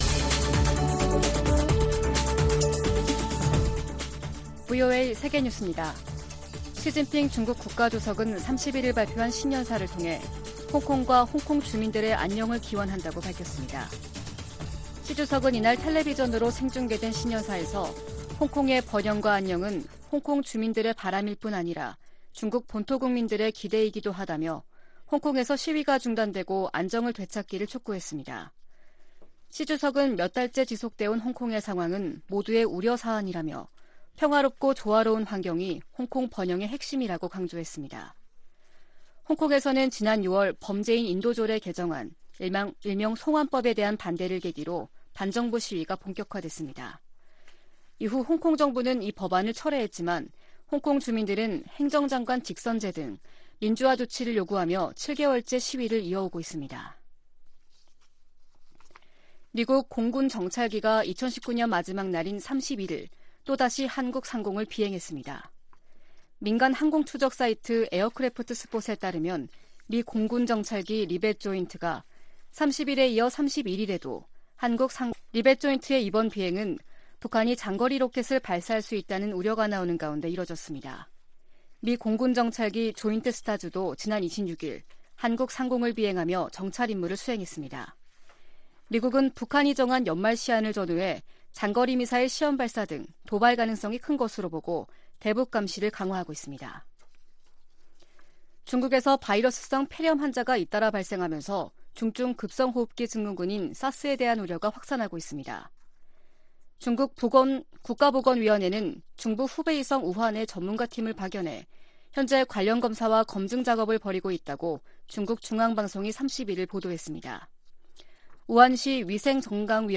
VOA 한국어 아침 뉴스 프로그램 '워싱턴 뉴스 광장' 2020년 1월 1일 방송입니다. 북한의 노동당 전원회의가 길어지면서 그만큼 북한이 내년 한 해를 대단히 엄중하고 무겁게 바라보고 있다는 분석이 나옵니다. m미국 백악관은 도널드 트럼프 대통령과 김저은 북한 국무위원장의 파눔ㄴ점 회동을 올해 주요 성과로 곱았습니다.